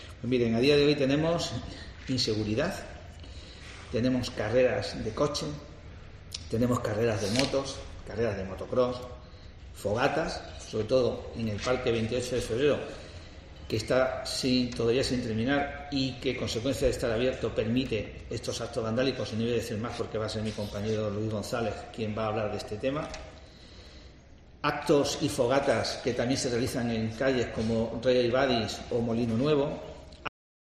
Carlos Ruiz Cosano, concejal del PP